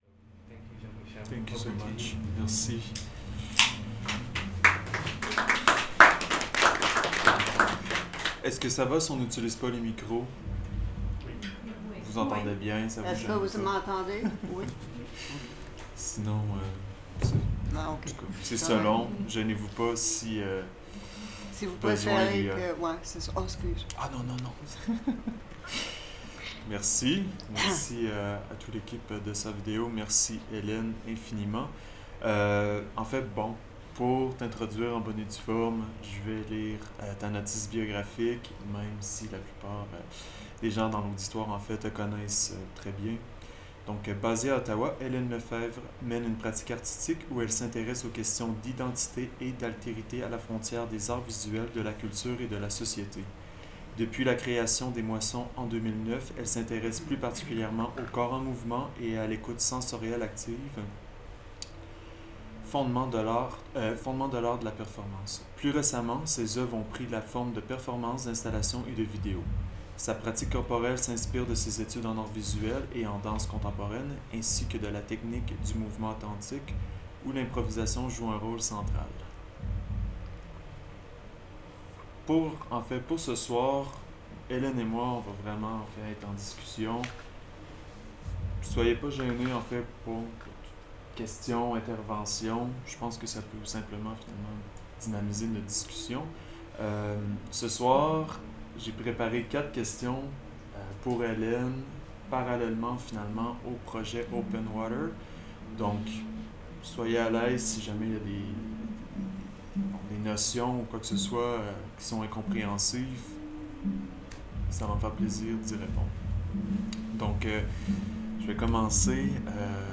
Presented as part of Knot Projections 2019: Imagining Publics, a city-wide public art exhibition in Ottawa running from July-November 2019, featuring five new commissions by Ottawa artists at four outdoor locations.